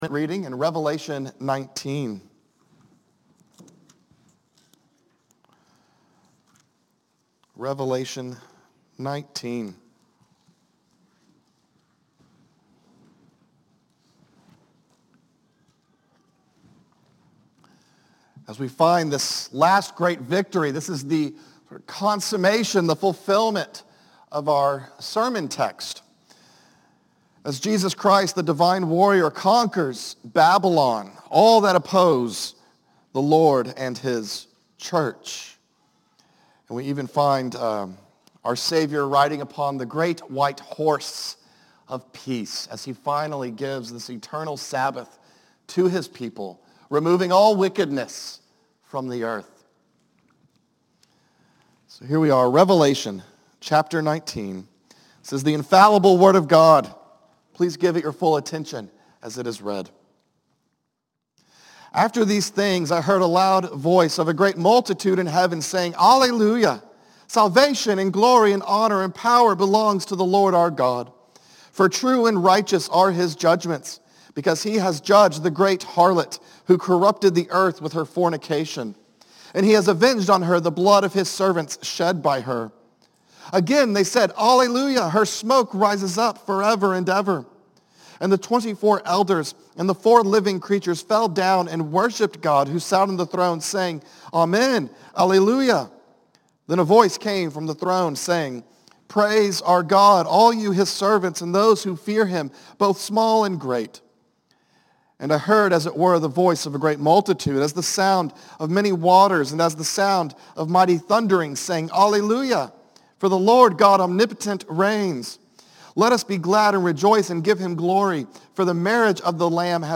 Sermons – Trinity Presbyterian